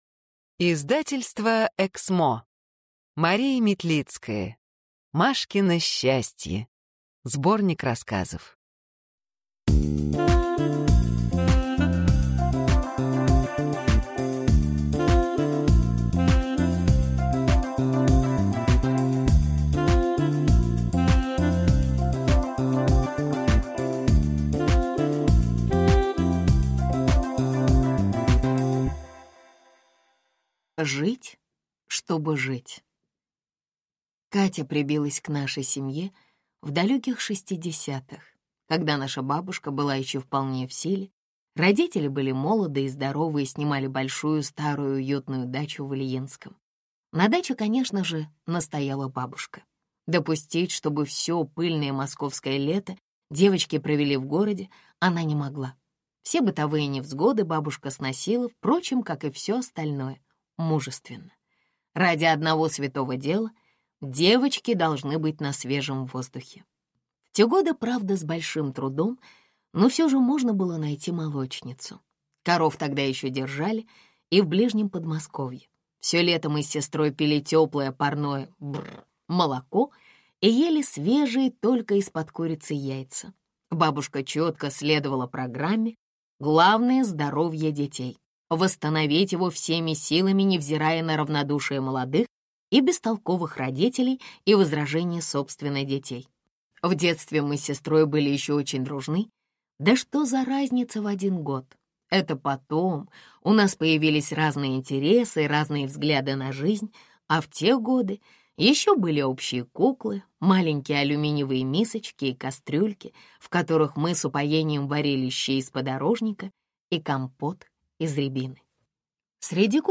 Аудиокнига «Имперский курьер. Том 3».